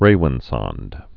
(rāwĭn-sŏnd)